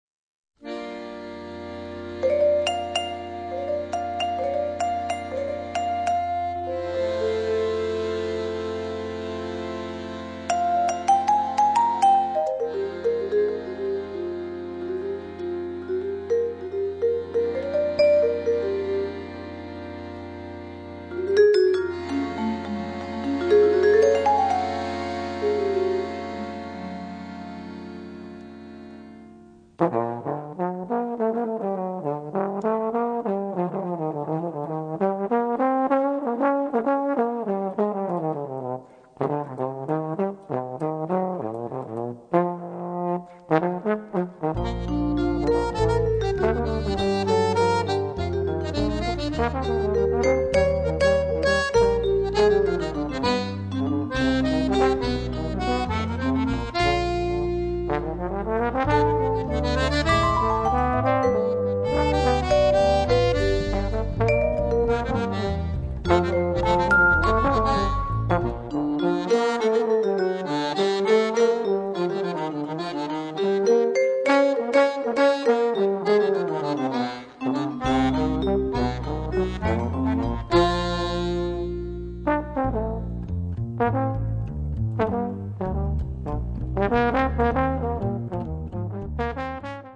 Auditorium di S. Cecilia - Perugia